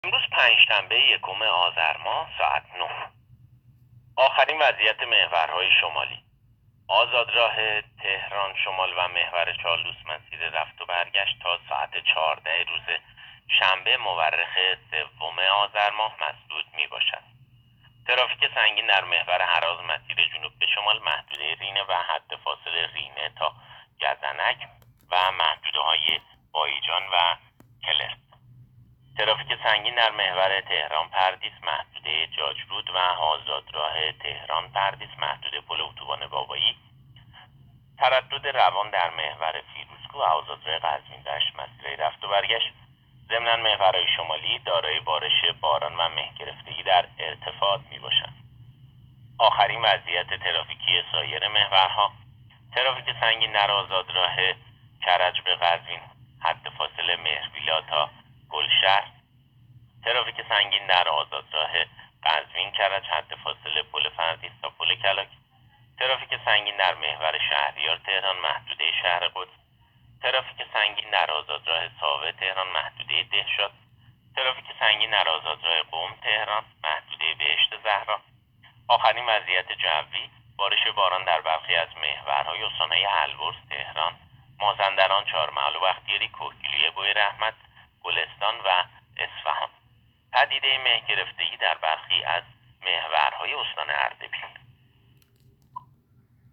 گزارش رادیو اینترنتی از آخرین وضعیت ترافیکی جاده‌ها تا ساعت ۹ اول آذر؛